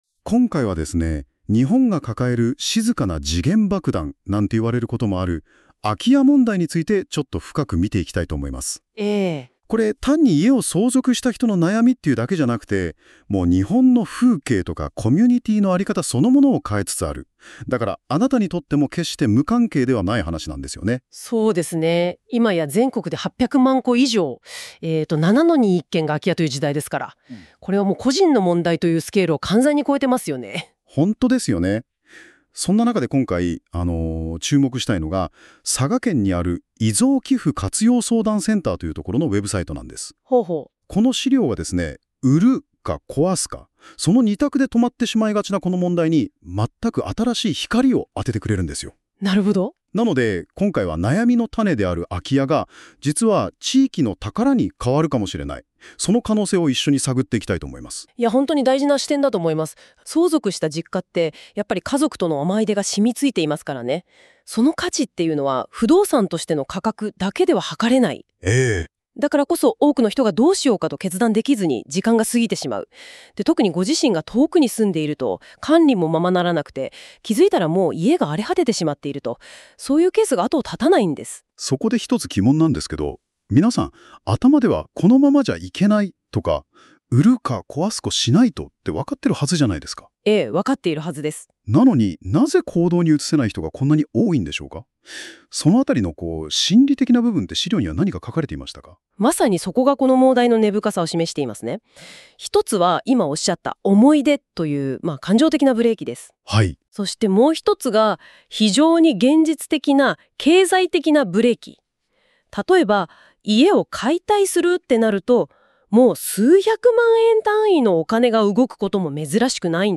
このページの内容をAI要約音声で聞きたい場合は、下の再生ボタンをクリック